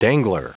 Prononciation du mot dangler en anglais (fichier audio)
Prononciation du mot : dangler